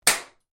Звуки ударов, пощечин
Тихая